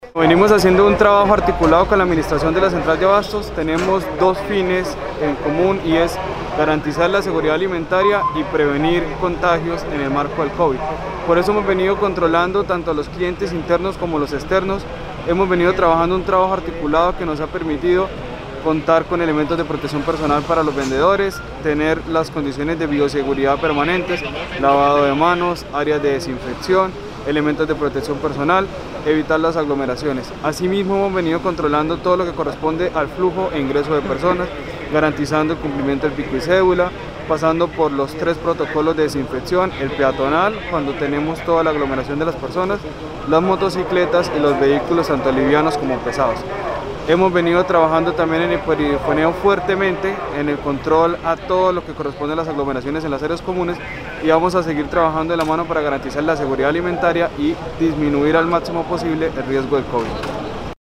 Henry Andrés Sarmiento Sierra, subsecretario de Ambiente de Bucaramanga
Henry-Andrés-Sarmiento-Sierra-subsecretario-de-Ambiente-de-Bucaramanga.mp3